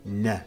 нэ no